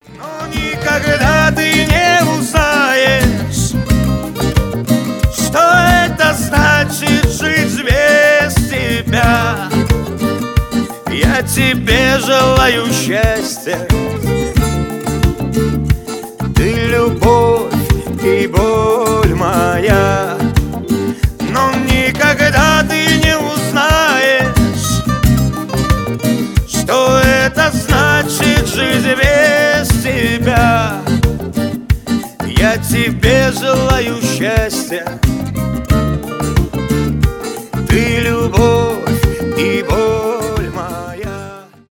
грустные , шансон